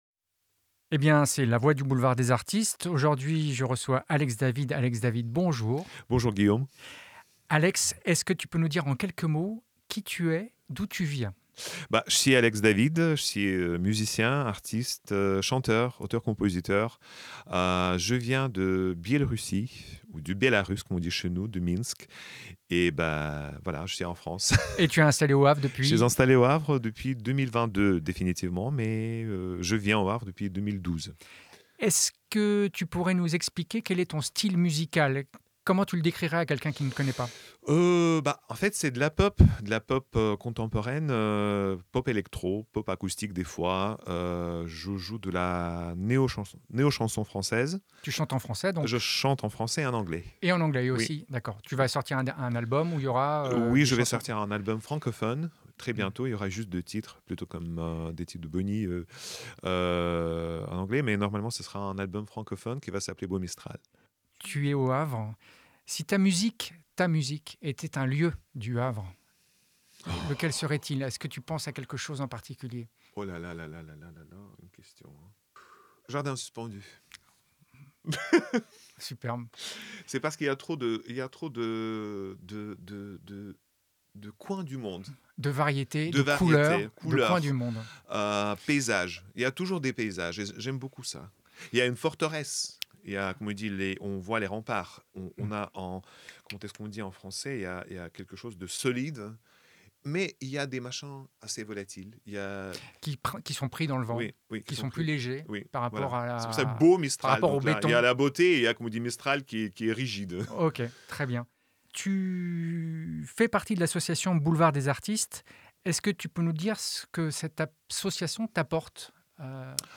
3 questions et une chanson pour découvrir ou redécouvrir un talent faisant partie de l'association du Boulevard Des Artistes Solidaires.